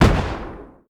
EXPLOSION_Arcade_14_mono.wav